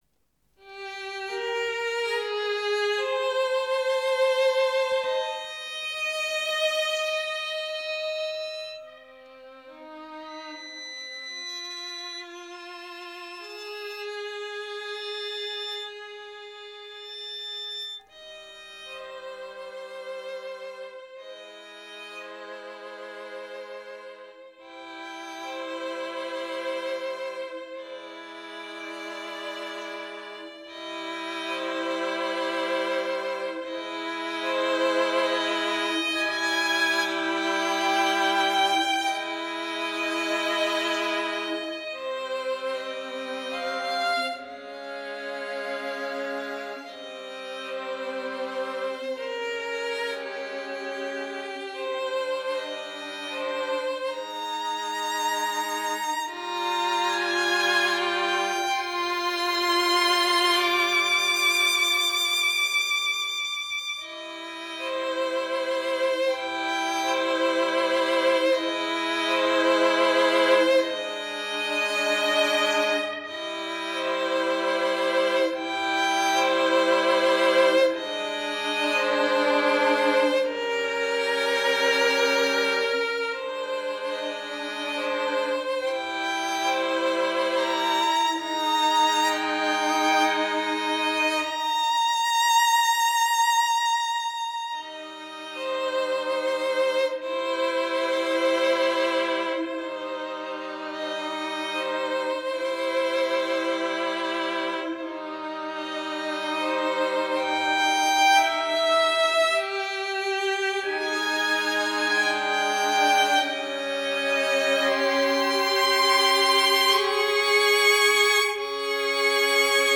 string orchestra
trumpets
transverse flutes
percussion
clarinet
orchestral work in 7 pieces (21:15)